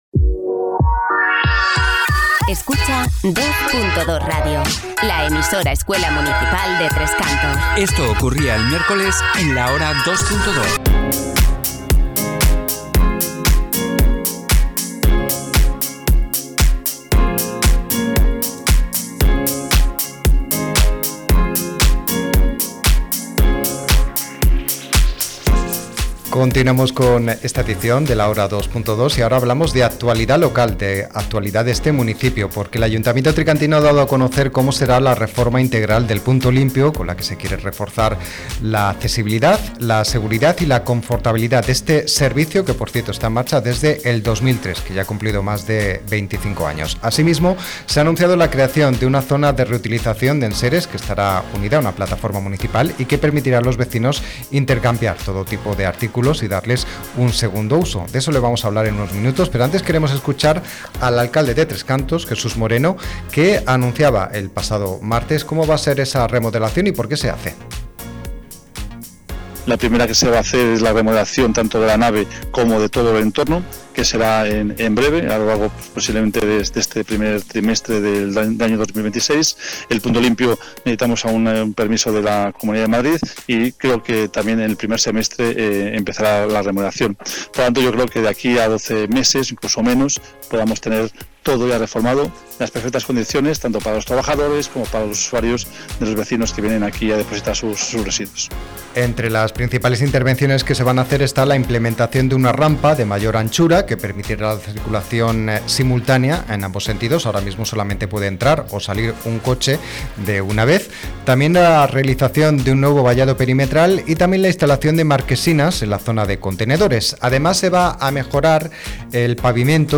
REPORTAJE-Mejoras-en-el-Punto-Limpio-de-Tres-Cantos.mp3